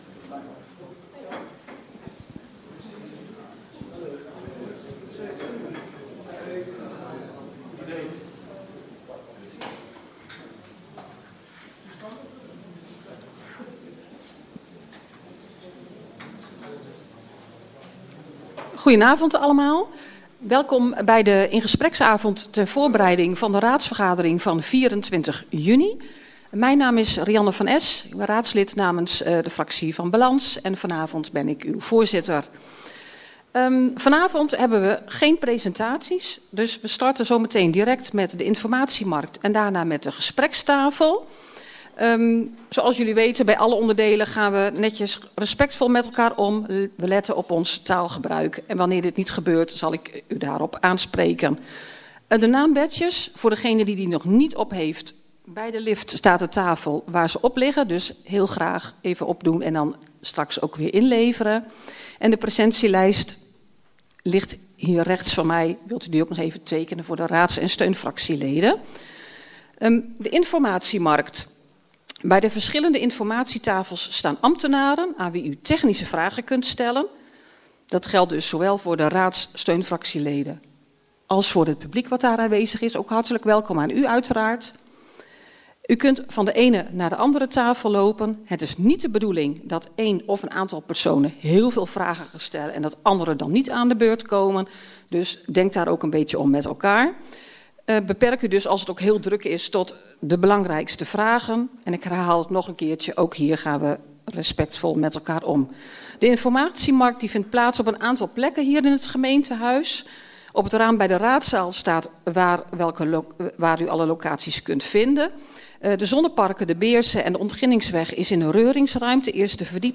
Deze bijeenkomst vindt plaats in het gemeentehuis.